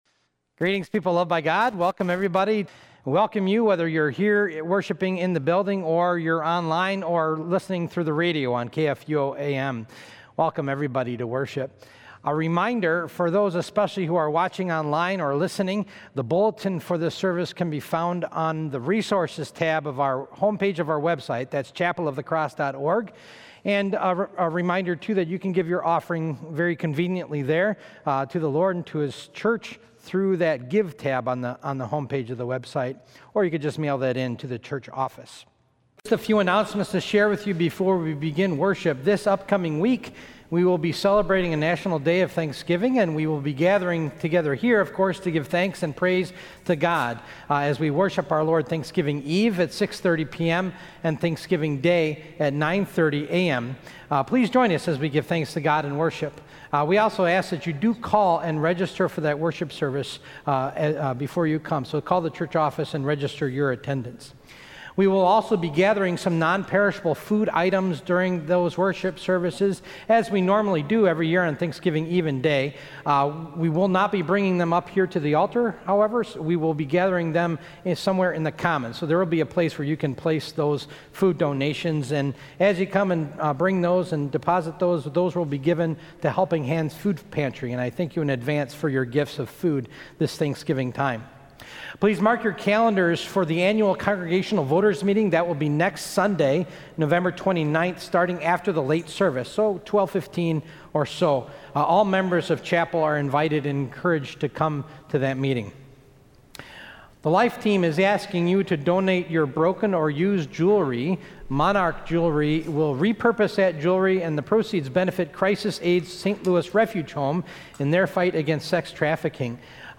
Sunday Worship 11-22-20 (Christ the King Sunday)